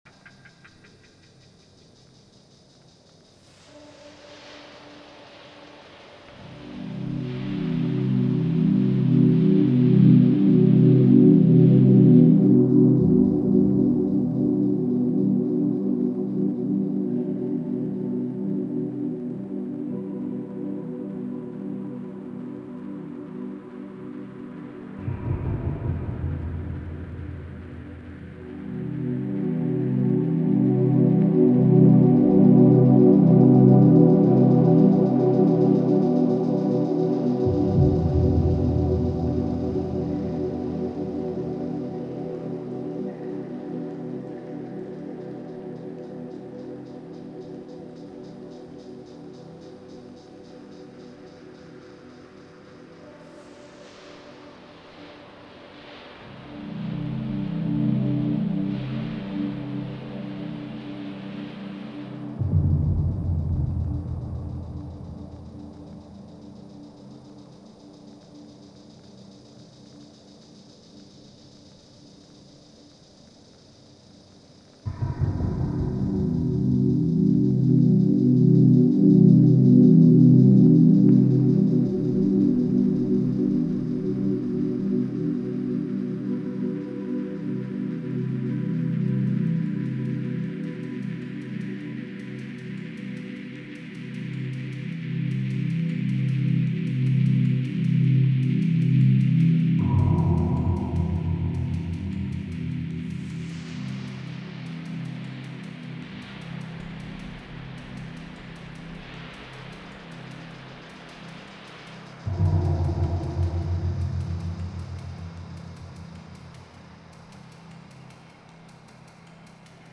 ambiances sonores spa bien-être massage
Rythmique, Relaxant, Contemplatif, Méditation